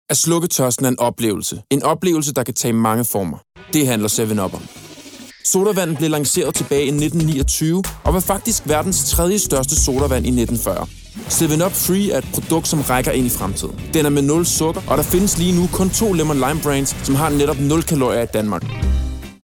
Mand
20-30 år
Reklame 1